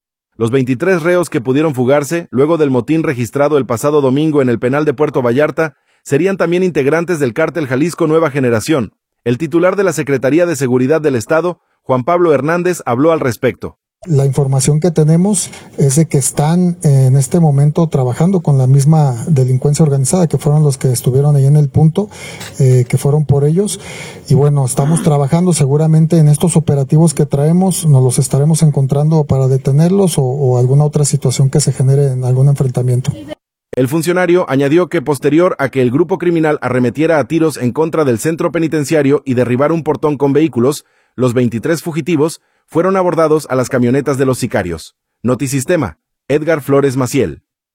Los 23 reos que pudieron fugarse, luego del motín registrado el pasado domingo en el penal de Puerto Vallarta, serían también integrantes del Cártel Jalisco Nueva Generación. El titular de la Secretaría de Seguridad del Estado, Juan Pablo Hernández habló al respecto.